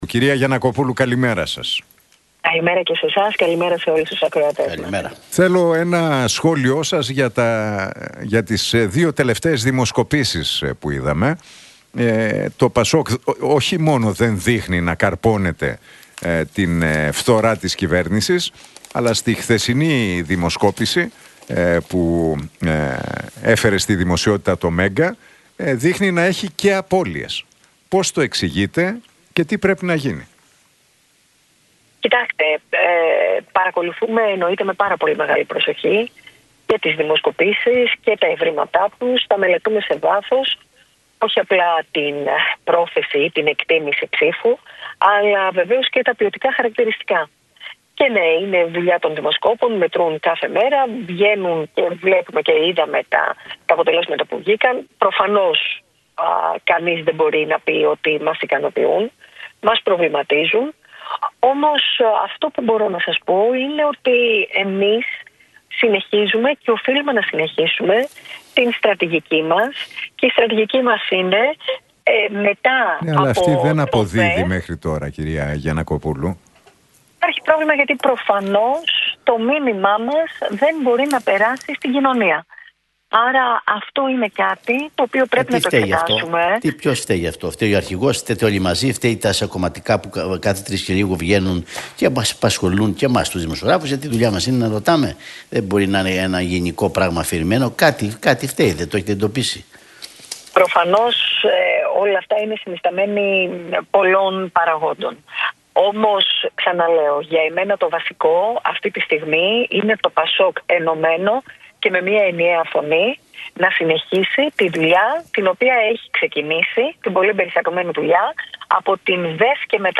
Γιαννακοπούλου στον Realfm 97,8: Μας προβληματίζουν οι δημοσκοπήσεις - Προφανώς το μήνυμά μας δεν μπορεί να περάσει στην κοινωνία